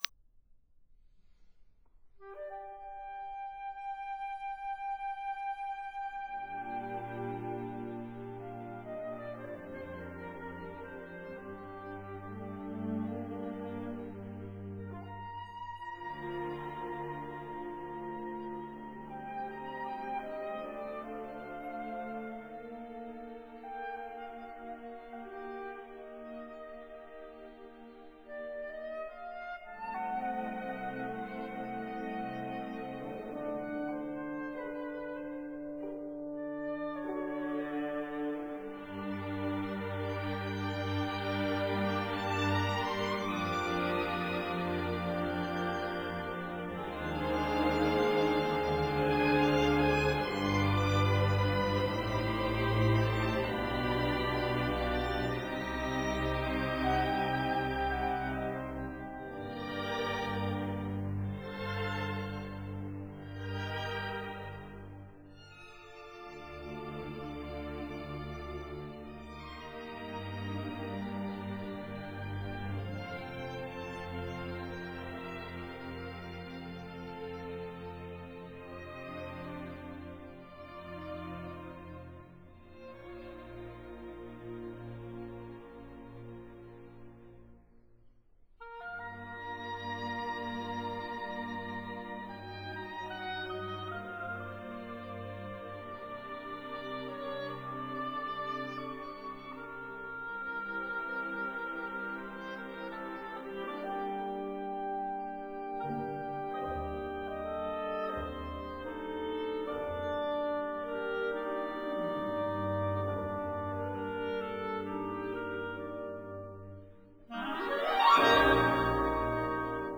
Overture for Orchestra